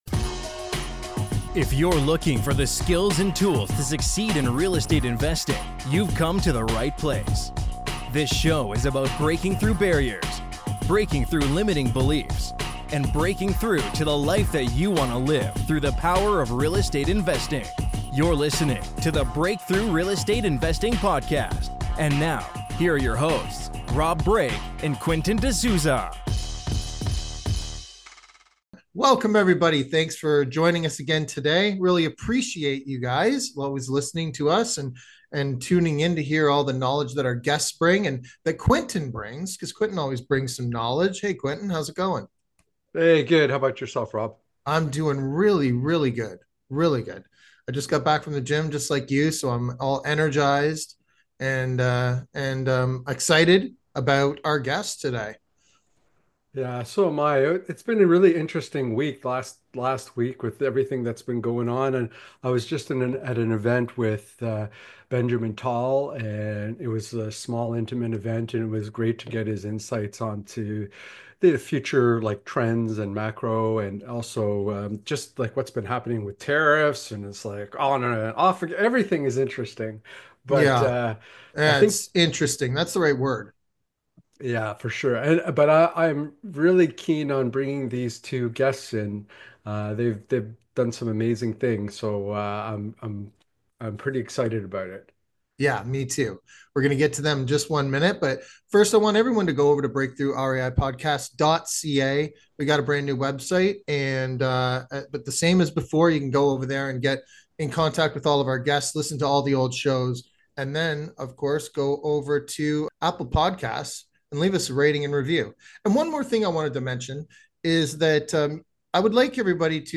Here's what you'll learn in our interview